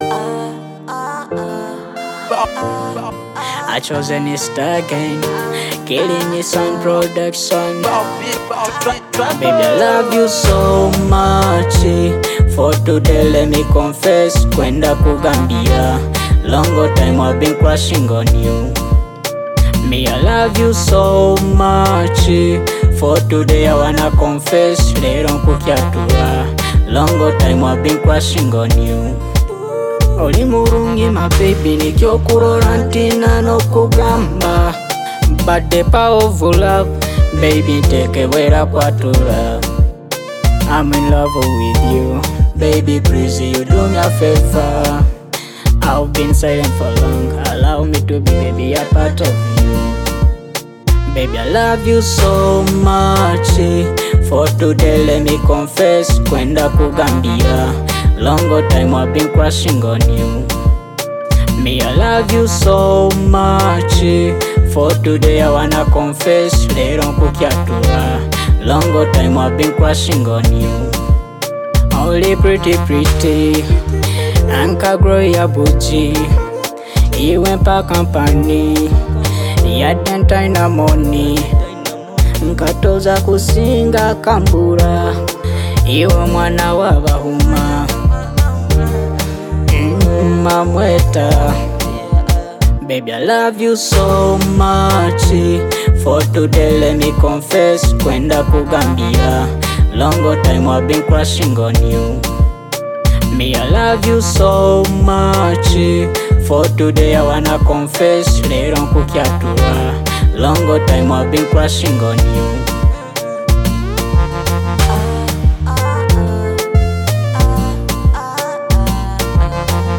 Genre: Luga Flow